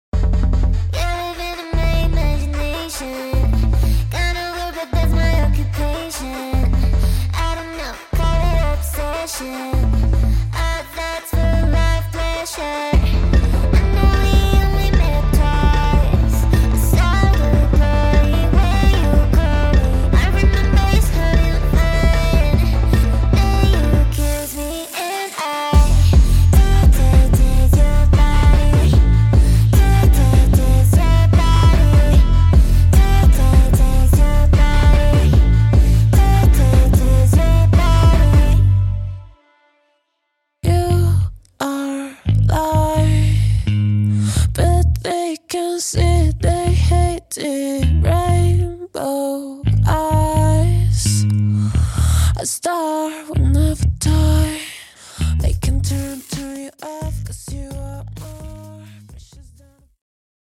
• 3 Atmospheric Vocals
• 33 Drum Loops